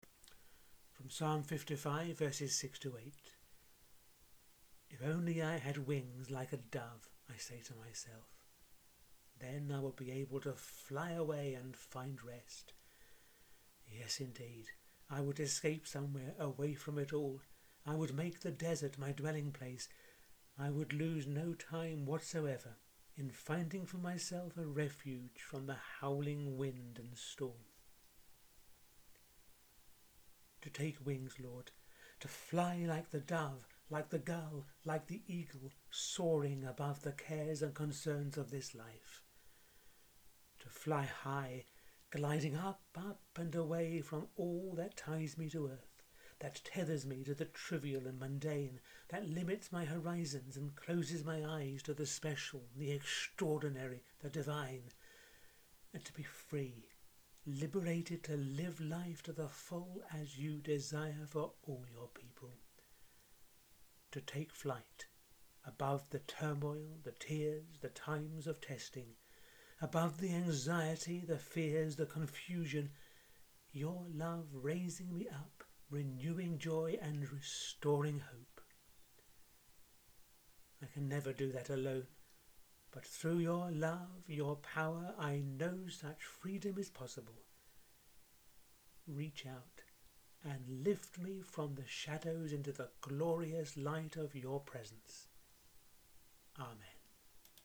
The fourteenth prayer in the audio series I’m running over these few weeks: